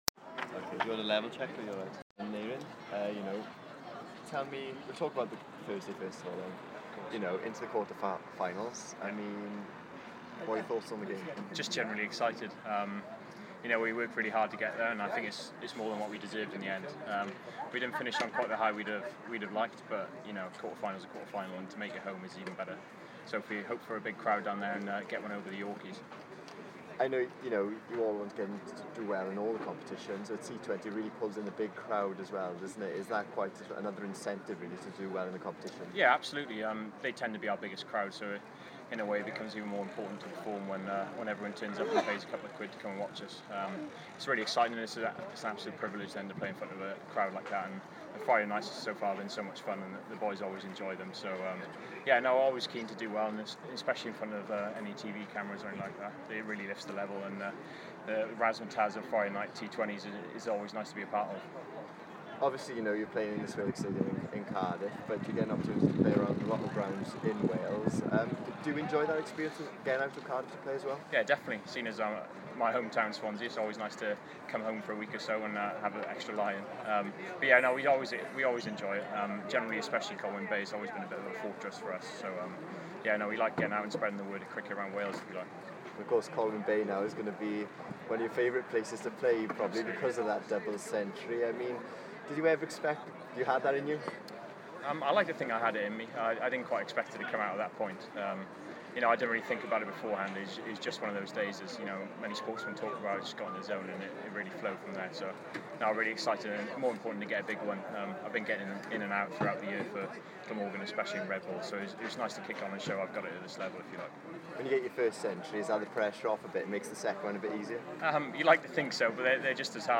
BBC Wales today interview